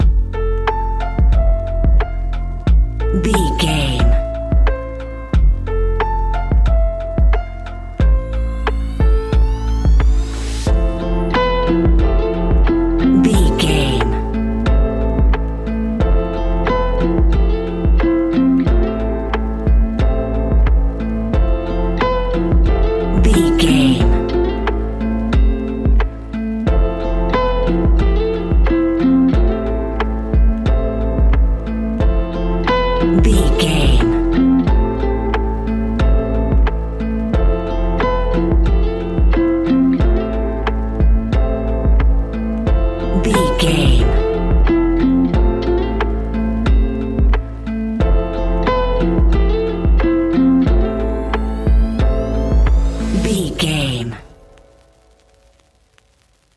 Ionian/Major
A♭
chilled
laid back
Lounge
sparse
new age
chilled electronica
ambient
atmospheric
morphing